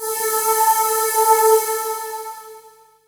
Index of /90_sSampleCDs/Techno_Trance_Essentials/CHOIR
64_02_voicesyn-A.wav